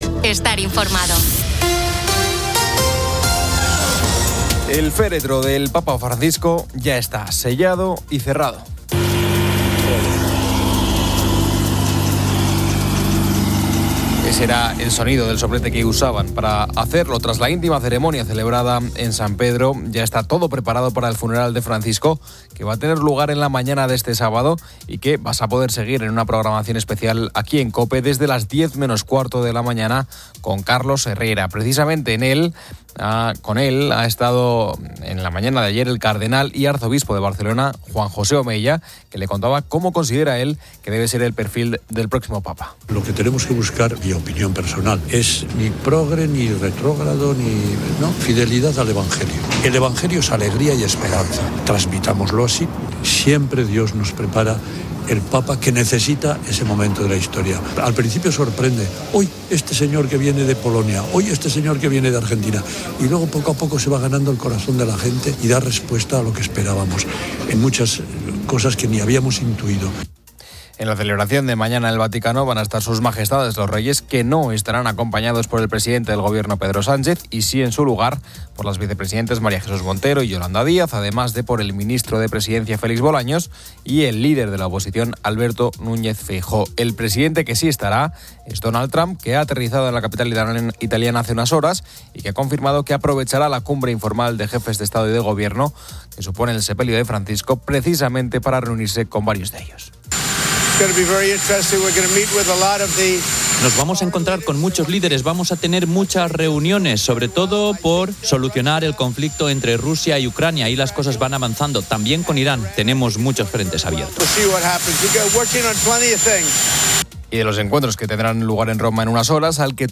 El féretro del Papa Francisco ya está sellado y cerrado. Ese era el sonido del soplete que usaban para hacerlo tras la íntima ceremonia celebrada en San Pedro.